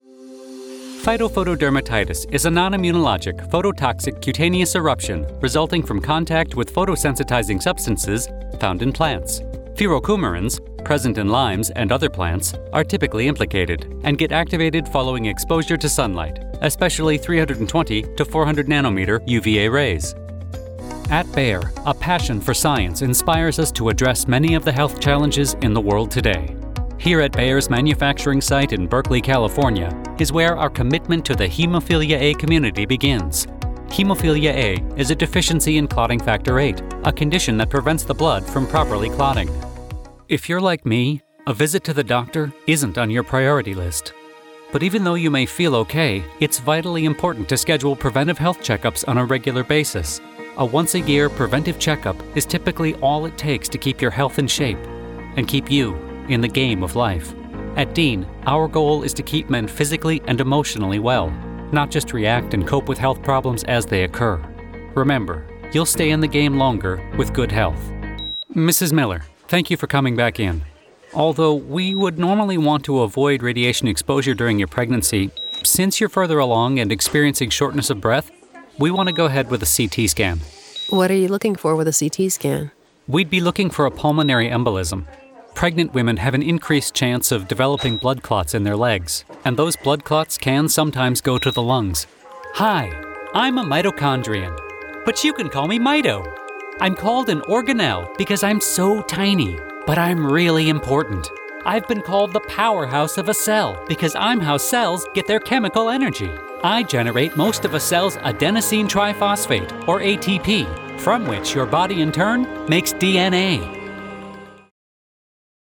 Medical Narration Showreel
Male
American Standard
Confident
Friendly
Reassuring